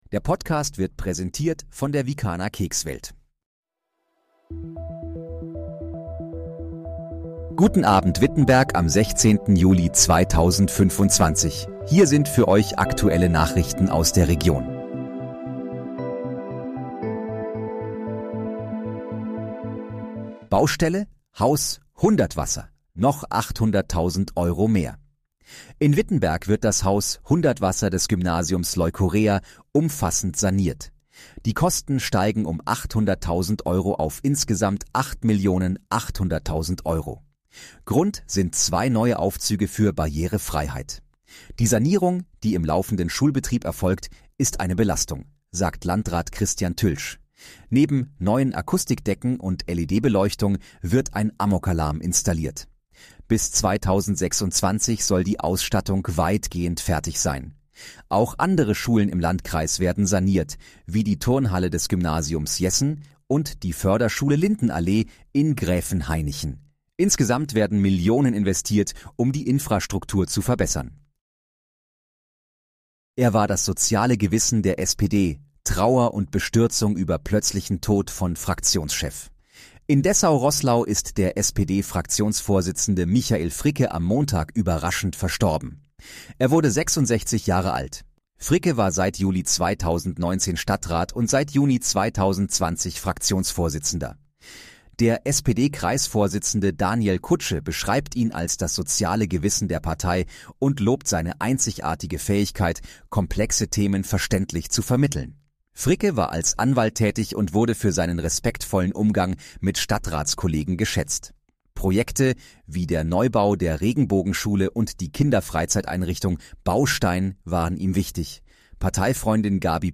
Guten Abend, Wittenberg: Aktuelle Nachrichten vom 16.07.2025, erstellt mit KI-Unterstützung
Nachrichten